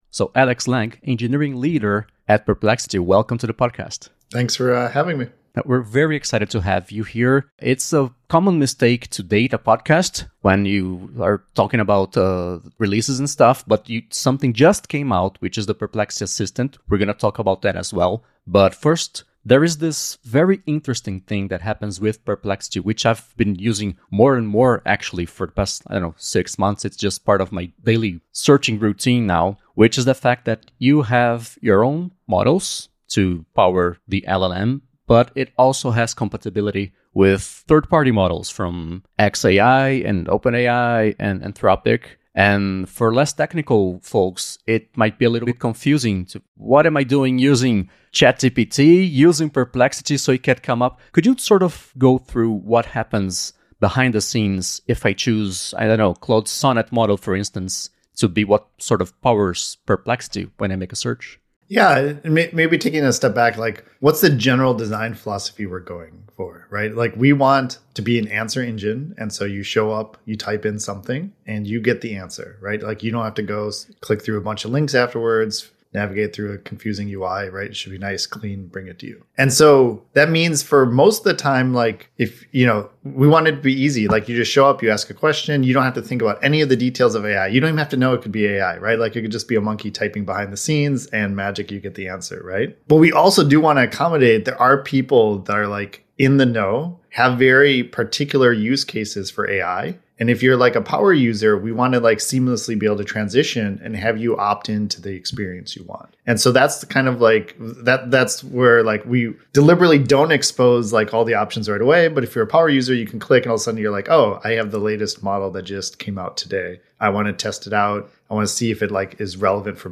Entrevista original em inglês